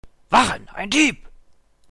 Speaker's Market - Deutsche Sprecher (m)
Dunkelelf bis Nord. Ich kann hier in einer guten Qualit�t aufnehmen und auch ein paar Effekte einf�gen, sowie den Ton per Equalizer ver�ndern bzw verfeinern.